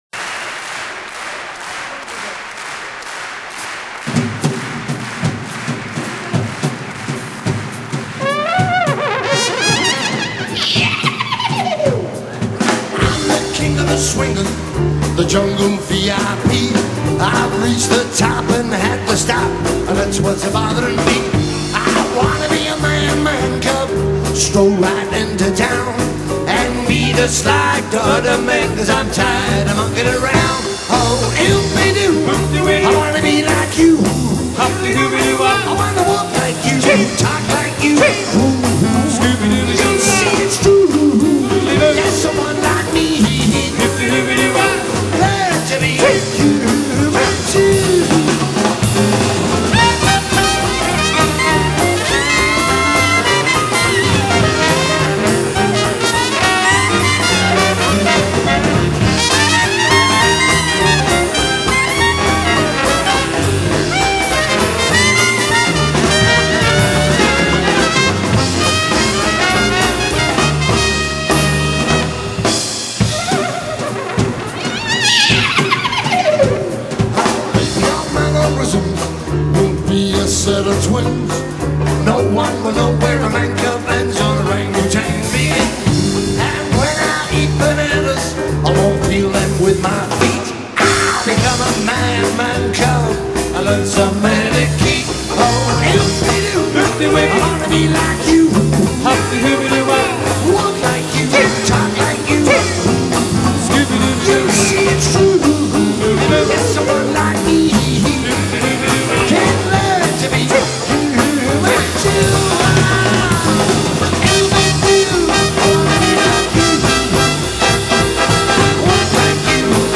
Genre: Jazz
Clarinet
Trombone
Piano
Clarinet/Saxophone
Trumpet
Drums
Bango
Bass